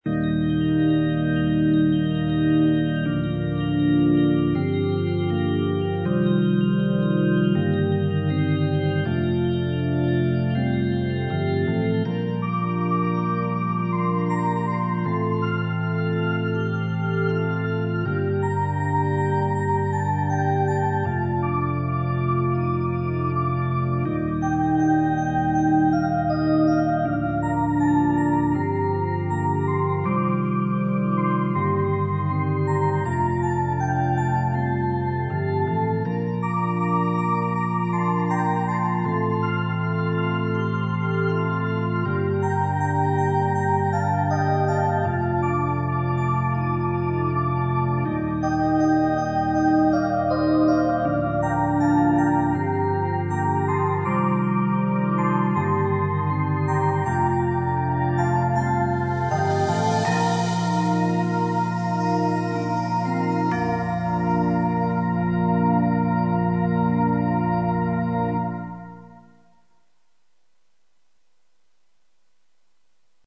２００３年１２月２２日〜２７日の期間限定でアップしていたクリスマス専用ＢＧＭです。
冒頭のチラチラした音で雪を表現してみました。オルガンやベルの音がなんとなくクリスマスっぽくありませんか？